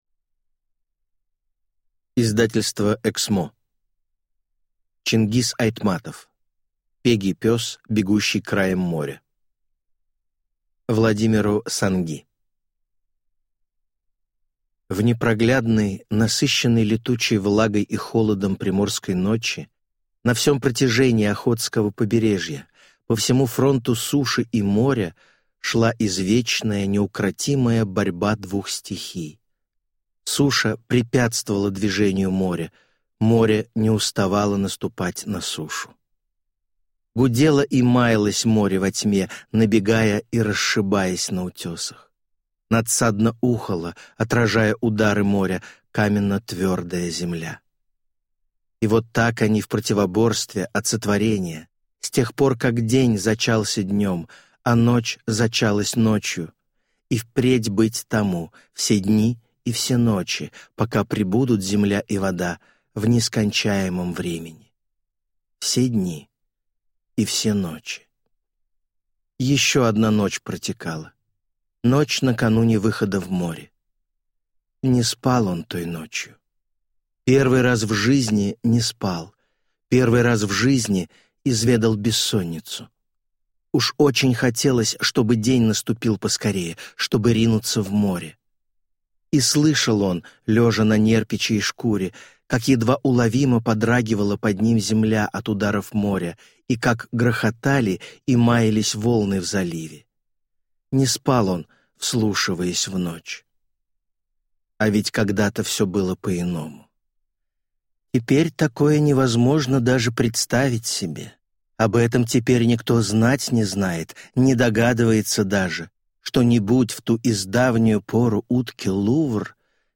Аудиокнига Пегий пес, бегущий краем моря | Библиотека аудиокниг
Прослушать и бесплатно скачать фрагмент аудиокниги